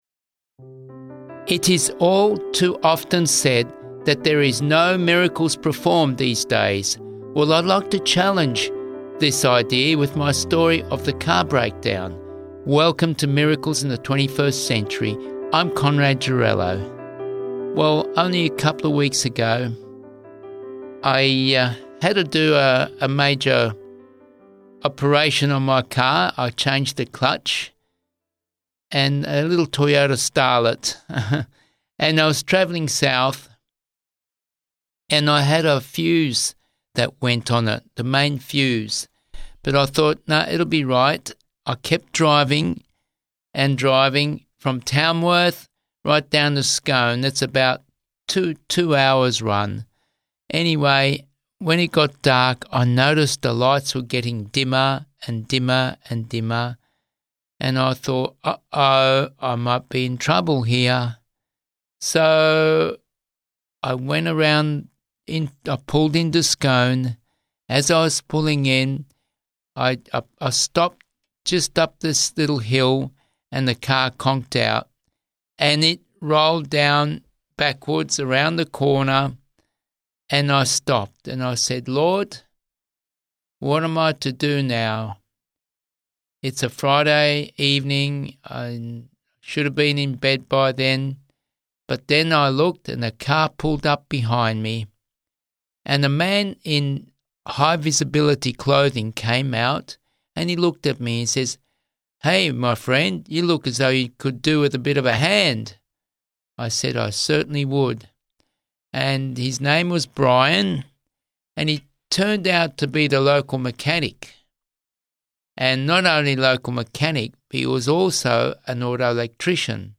Real-life Christian testimony of miracles in the 21st century and amazing answered prayer . Stranded on the highway, car breaking down twice, God sent help through strangers – powerful modern miracles that prove God still cares today.
Music Credits: